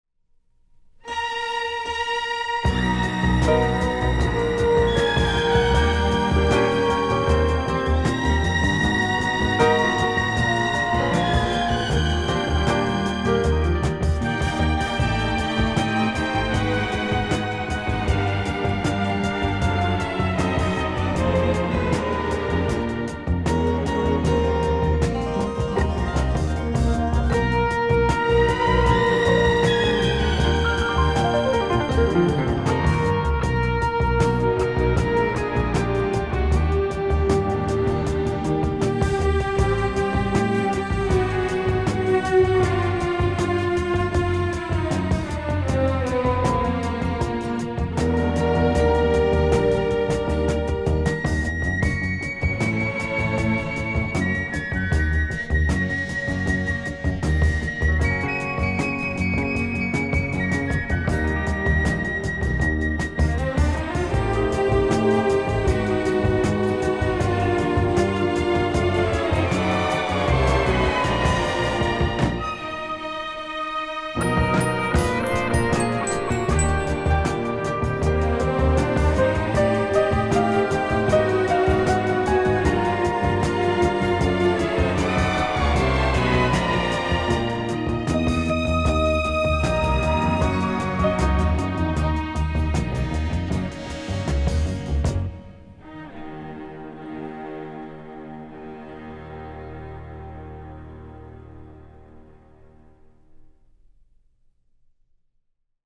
Genre ....... Easy Listening